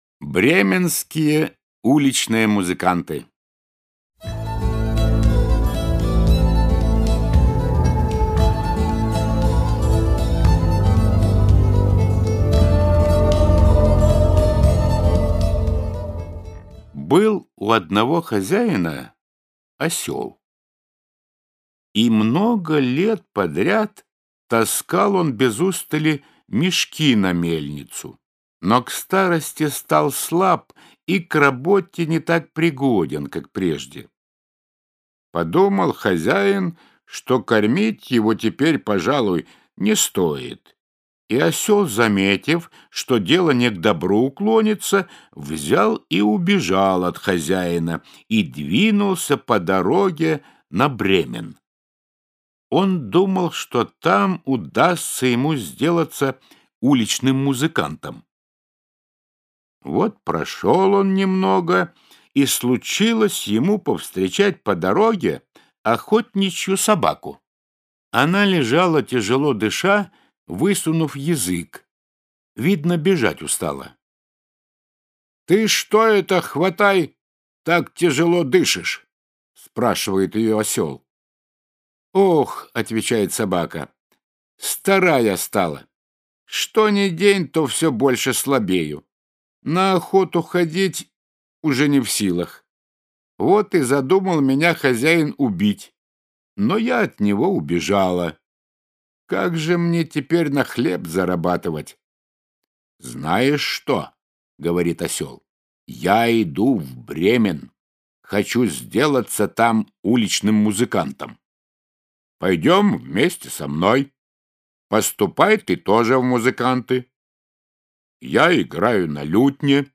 Аудиокнига Сказки братьев Гримм | Библиотека аудиокниг